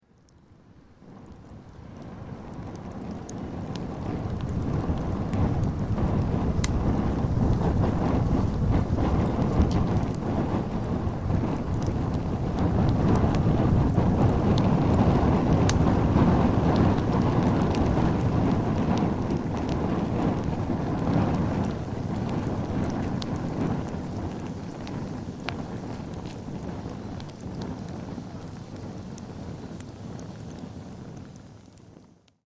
Fire-noise.mp3